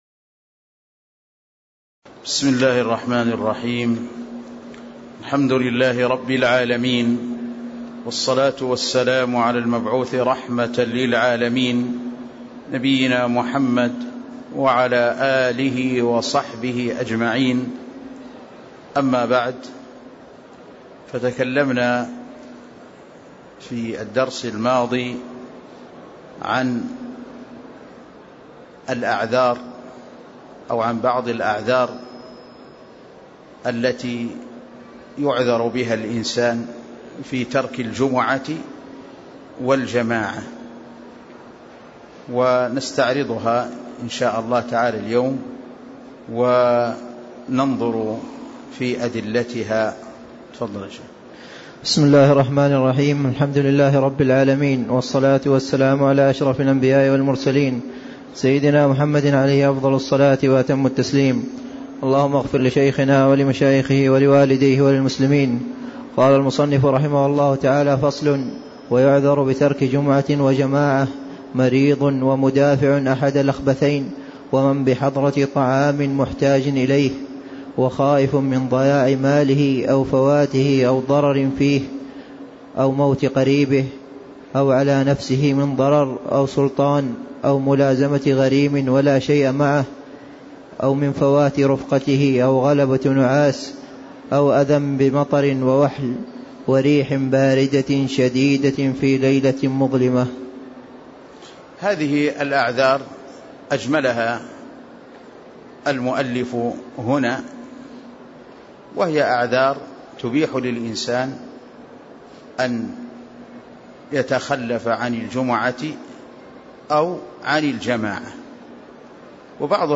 تاريخ النشر ١٧ صفر ١٤٣٦ هـ المكان: المسجد النبوي الشيخ